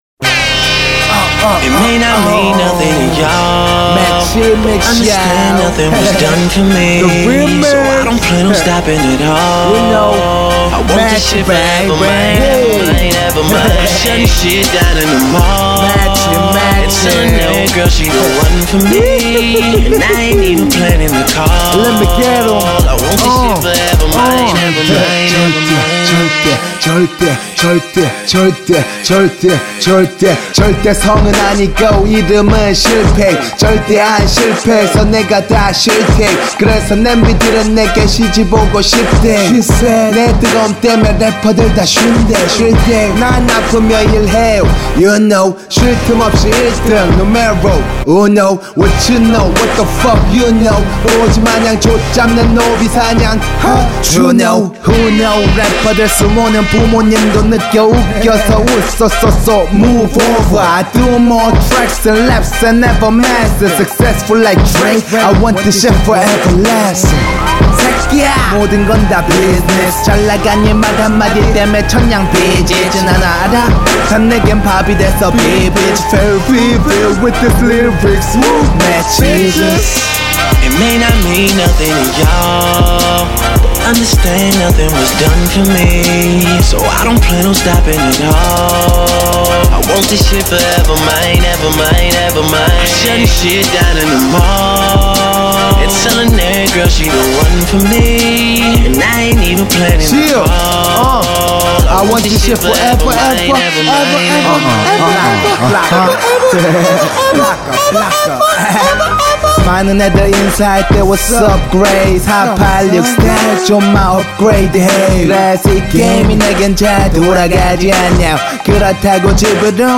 • [국내 / REMIX.]